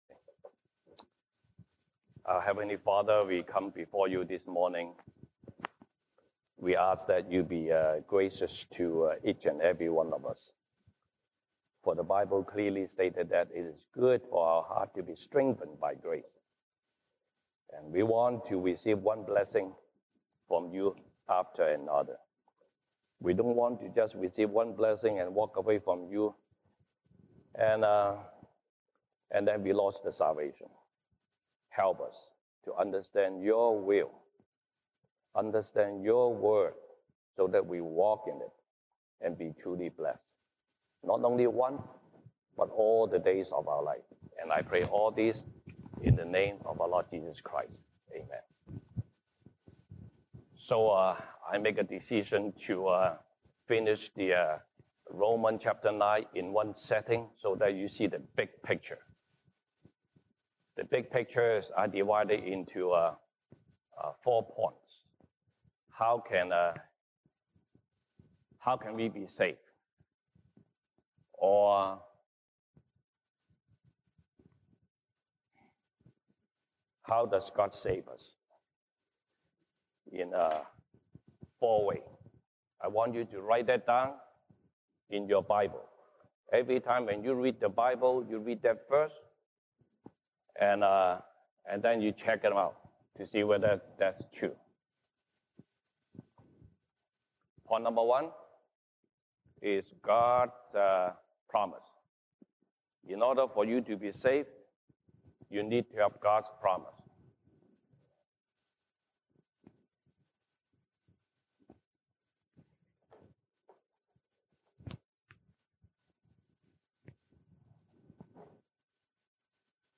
西堂證道 (英語) Sunday Service English: How does God save us?